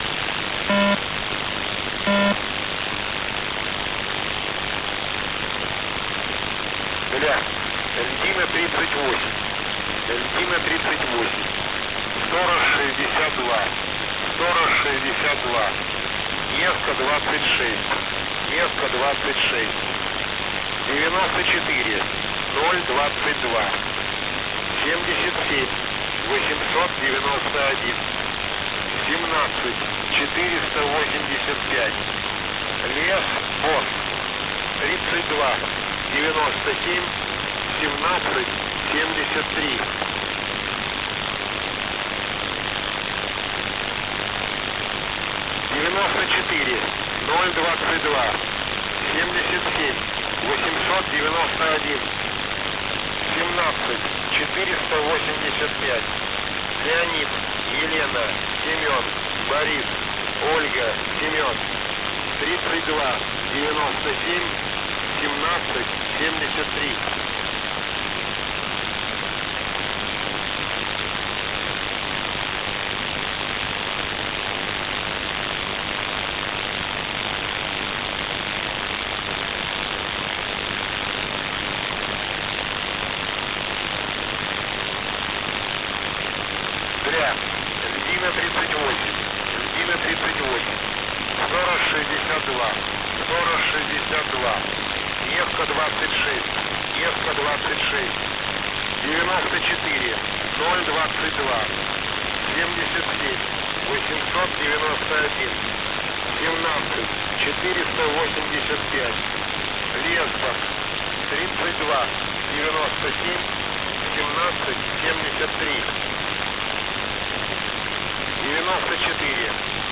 H3E (USB)